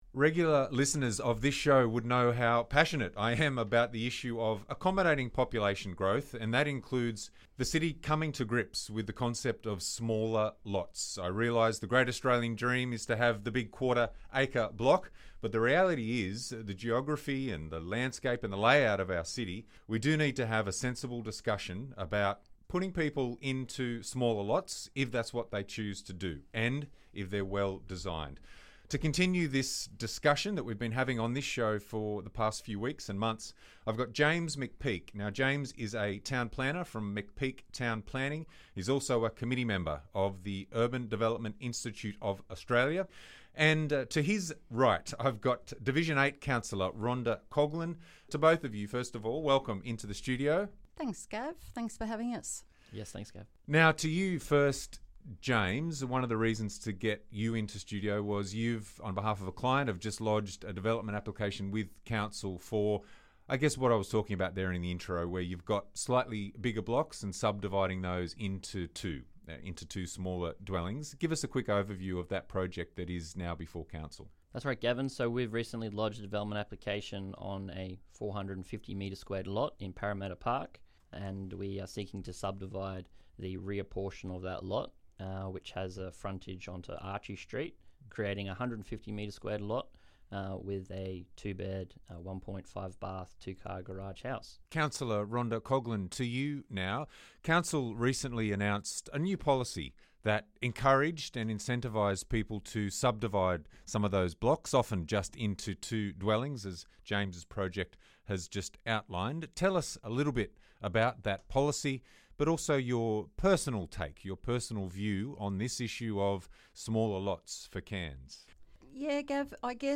roundtable discussion about small lots in Cairns